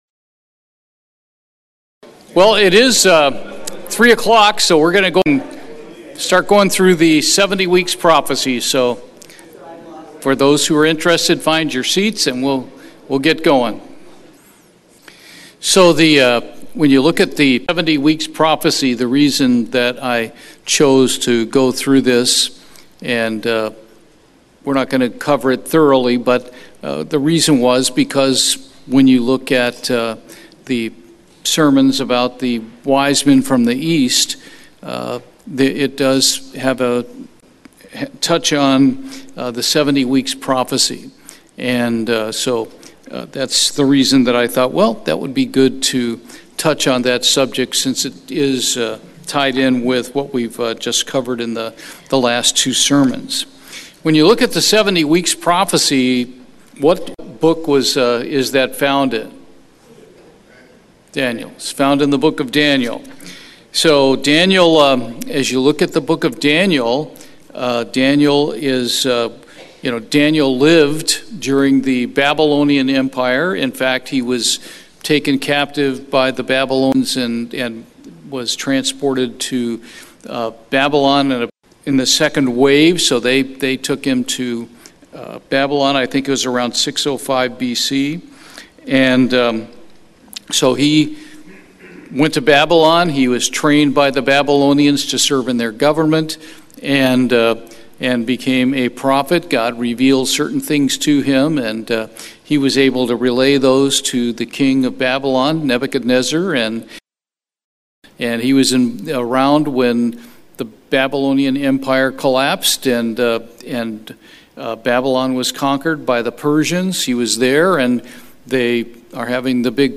Bible Study, Daniel 9